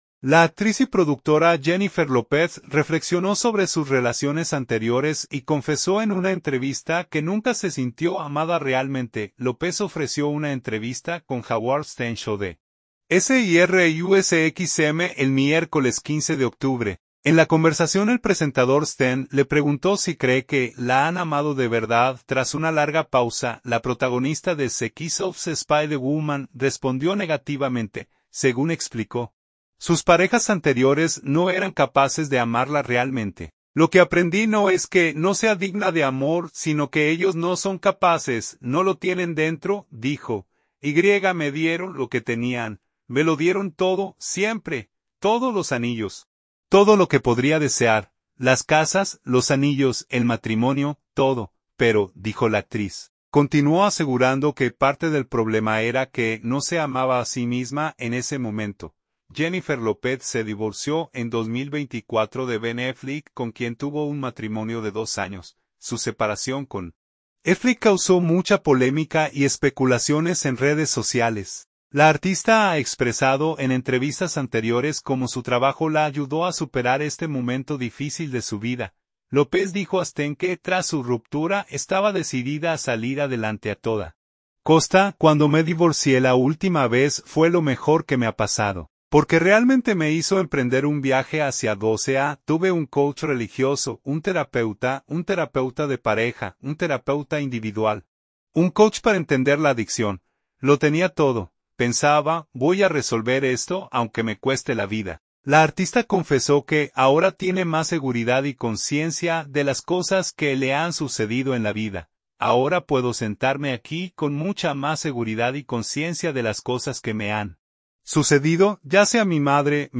Lopez ofreció una entrevista con Howard Stern Show de SiriusXM el miércoles 15 de octubre. En la conversación el presentador Stern le preguntó si cree que la han “amado de verdad”.